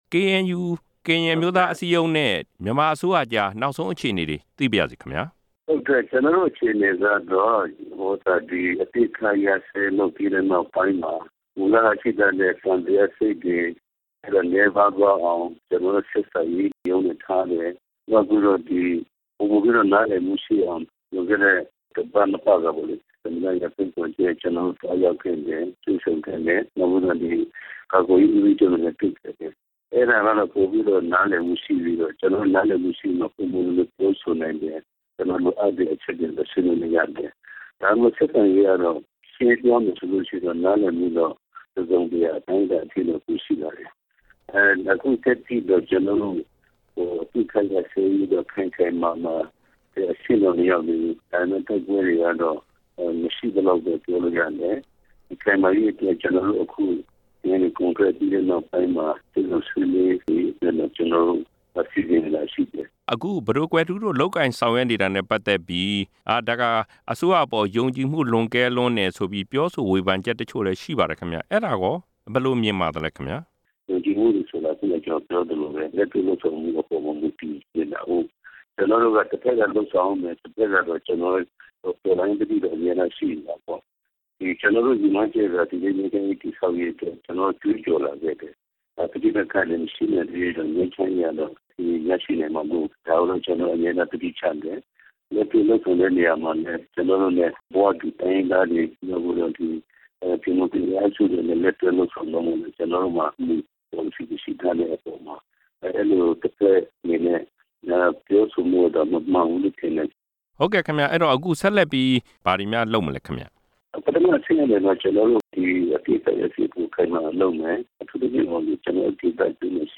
KNU နဲ့ မြန်မာအစိုးရရဲ့ လက်ရှိဆက်ဆံရေး အခြေအနေအပေါ် မေးမြန်းချက်